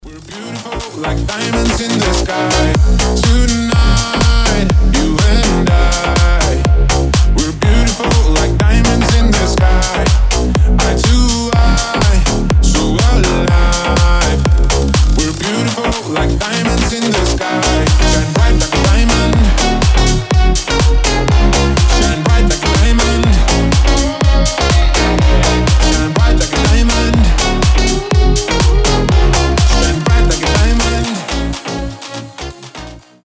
• Качество: 320, Stereo
мужской голос
EDM
Cover
house
slap house